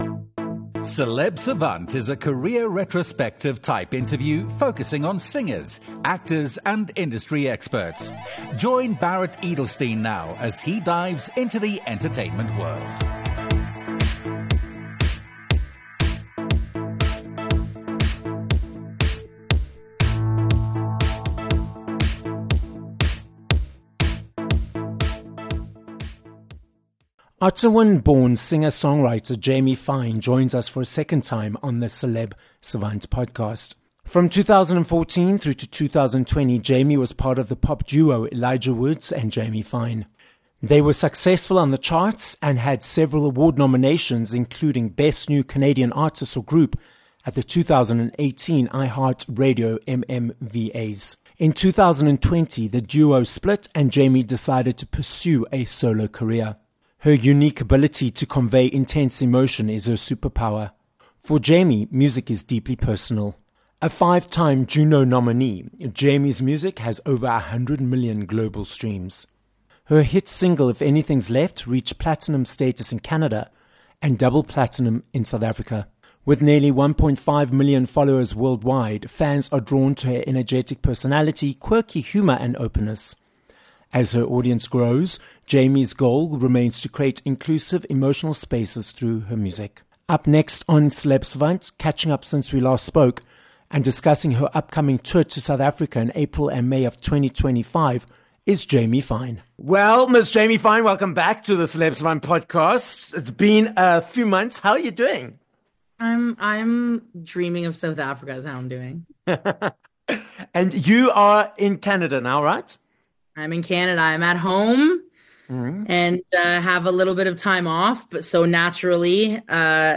Jamie Fine - a Canadian singer, songwriter, and 5-times Juno Award nominee - joins us for a second time on Celeb Savant. Jamie catches us up on what has been happening in her world since we last spoke, which includes why she became and what it means to be an independent artist, unpacks the world of social media, and her upcoming South African tour.